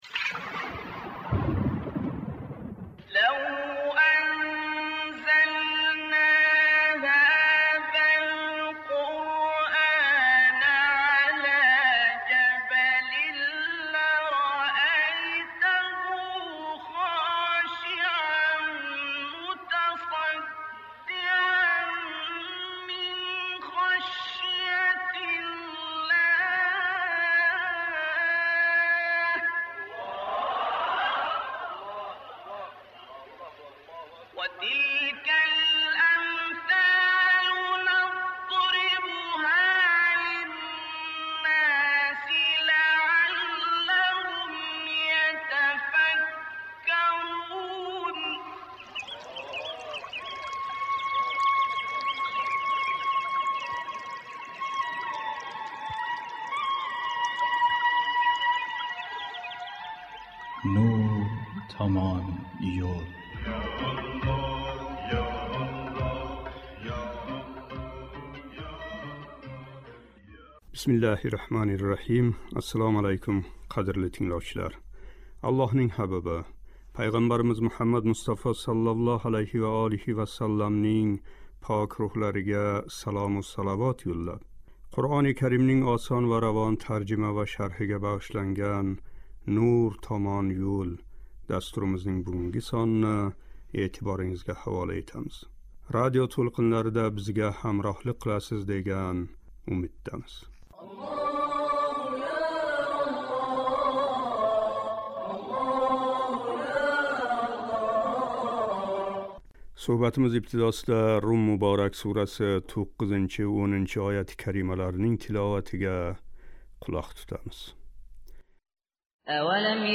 720-қисм.« Рум " муборак сураси 9-13-ояти карималарининг шарҳи Суҳбатимиз ибтидосида « Рум " муборак сураси 9-10-ояти карималарининг тиловатига қулоқ тутамиз